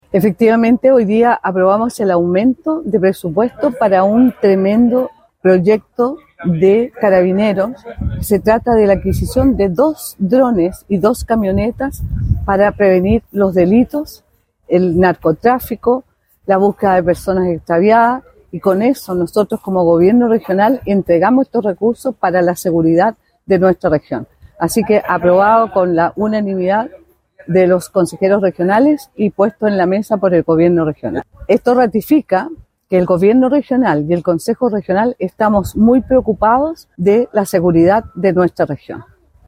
Tras el visto bueno del Consejo Regional de Los Lagos, la consejera regional y presidenta de la Comisión de Hacienda, María Angélica Barraza, comentó que es muy importante como Gobierno Regional entregar estos recursos para la seguridad de nuestra región.